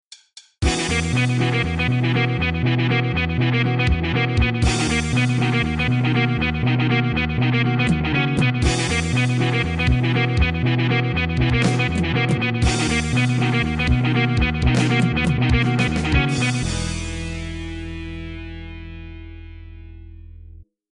こっちは爽やか。